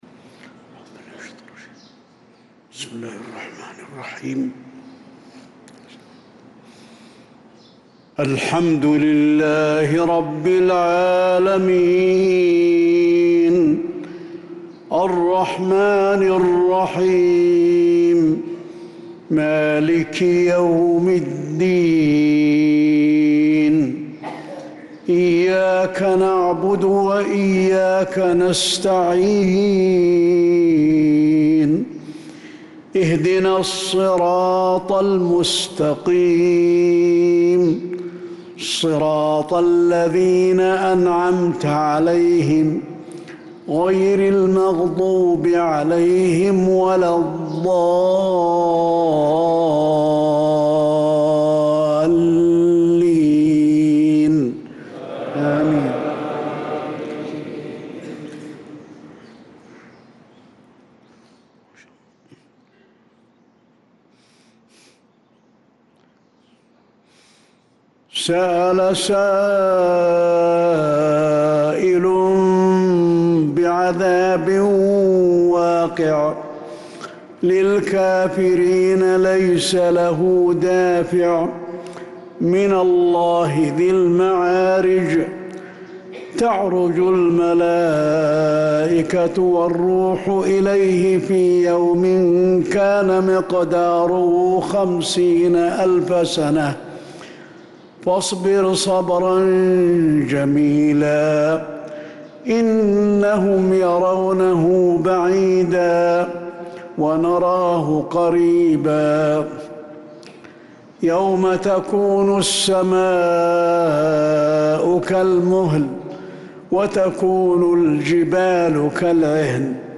صلاة الفجر للقارئ علي الحذيفي 3 ذو القعدة 1445 هـ
تِلَاوَات الْحَرَمَيْن .